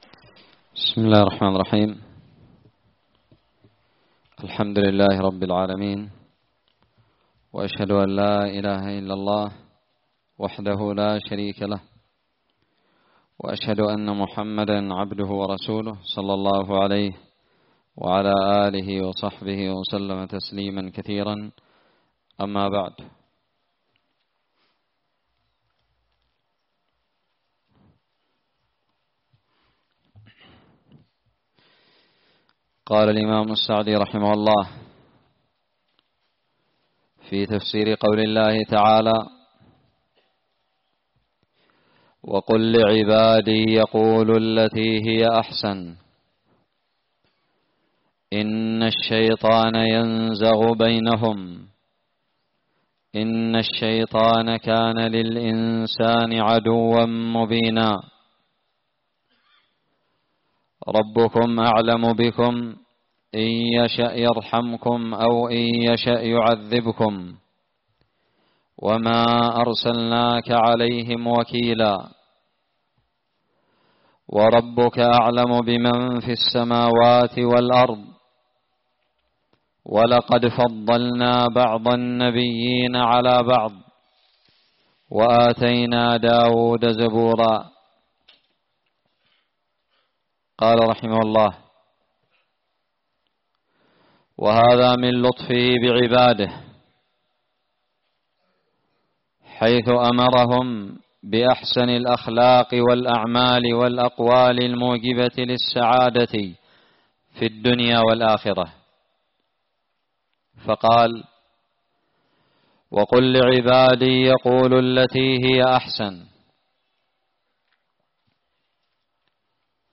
الدرس الرابع عشر من تفسير سورة الإسراء
ألقيت بدار الحديث السلفية للعلوم الشرعية بالضالع